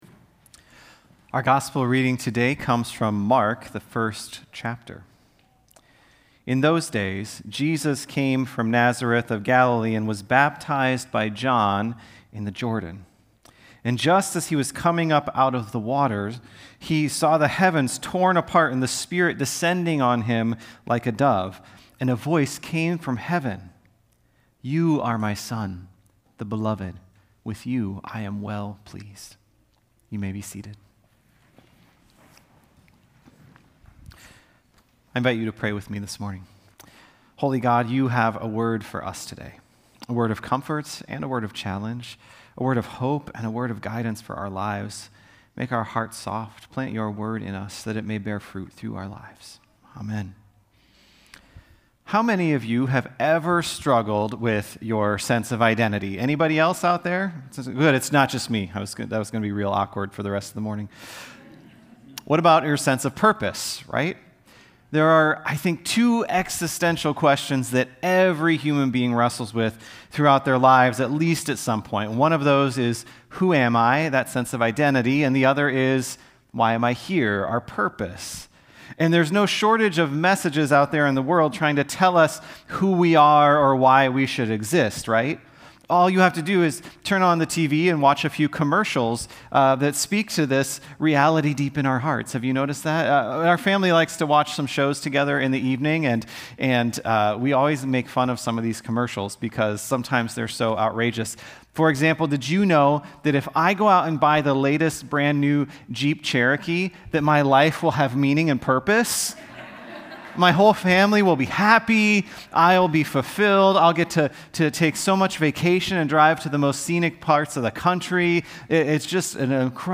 Sermons | Good Shepherd Lutheran Church